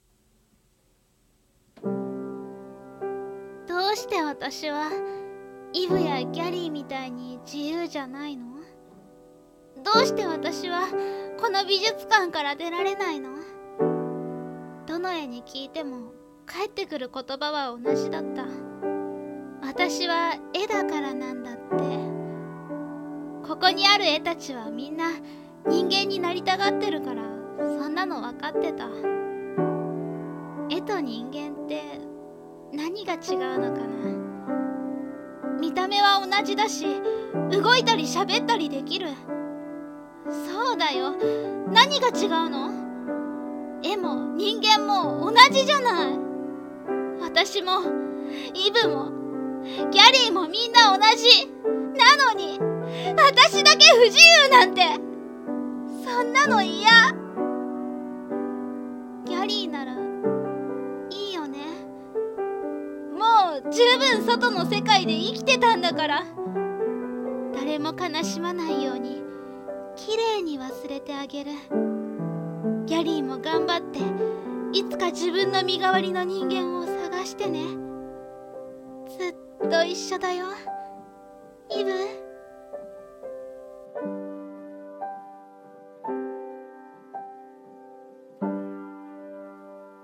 【声劇】Ibイヴ〔メアリー〕